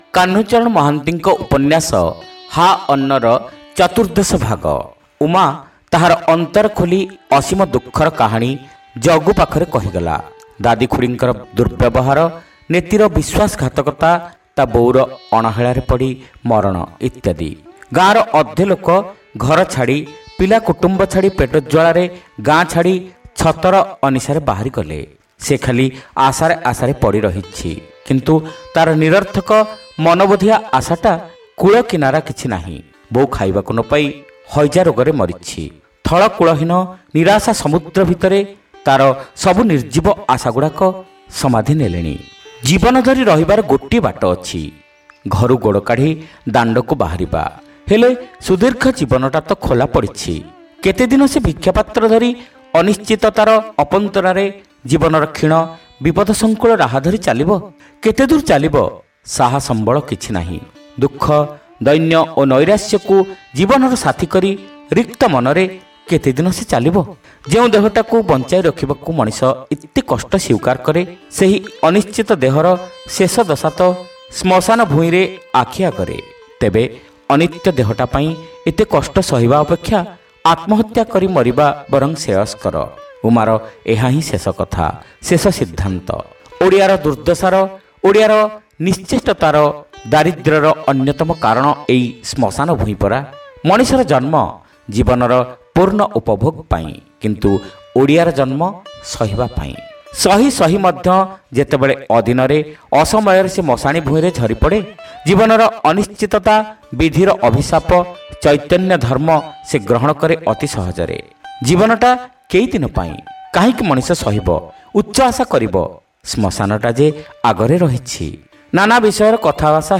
ଶ୍ରାବ୍ୟ ଉପନ୍ୟାସ : ହା ଅନ୍ନ (ଚତୁର୍ଦ୍ଦଶ ଭାଗ)